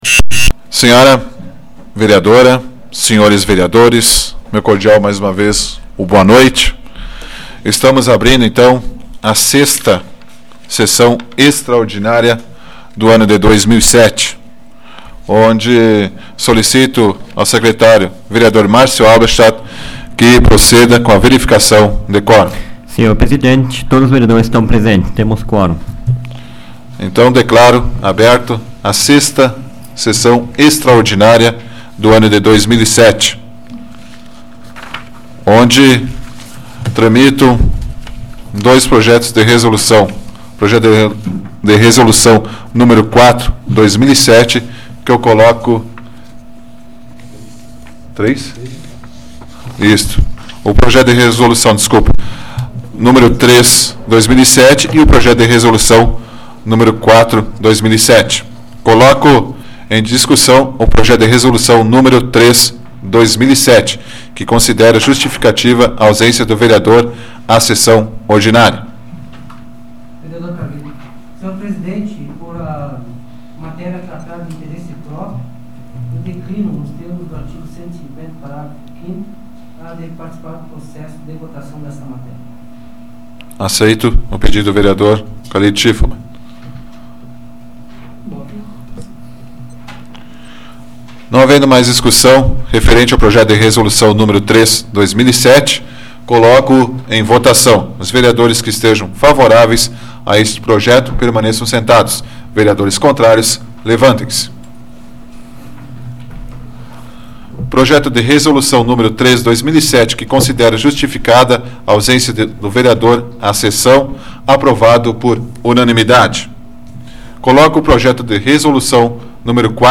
Áudio da 36ª Sessão Plenária Extraordinária da 12ª Legislatura, de 04 de junho de 2007